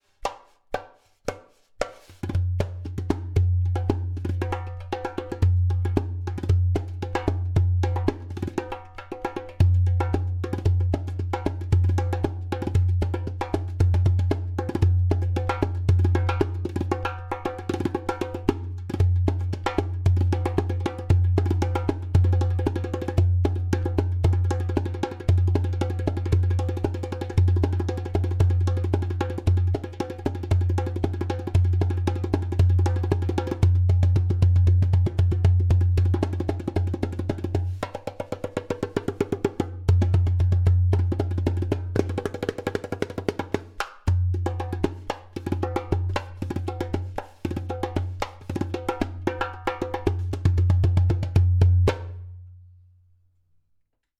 Premium Bronze 9.5 inch darbuka with goat Skin
115 bpm:
• Taks with harmonious overtones.
• Even tonality on around rim.
• Deep bass.
• Loud clay kik/click sound!